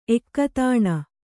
♪ ekkatāṇa